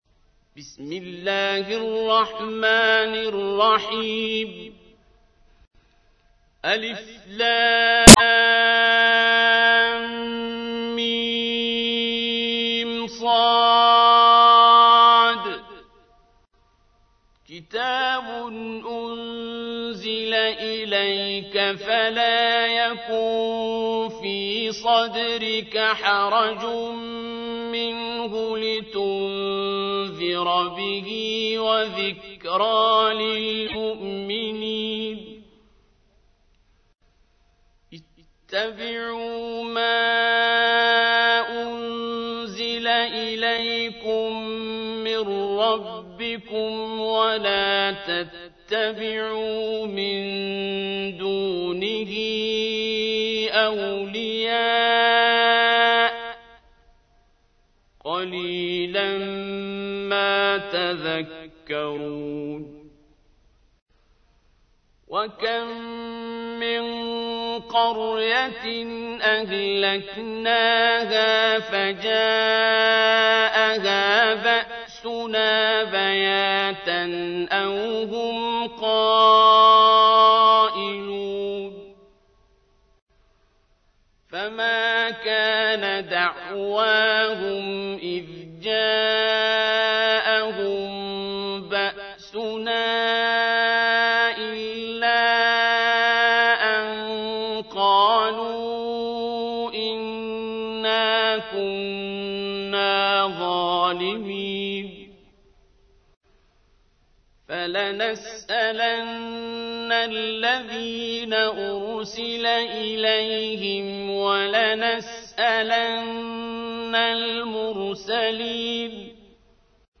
تحميل : 7. سورة الأعراف / القارئ عبد الباسط عبد الصمد / القرآن الكريم / موقع يا حسين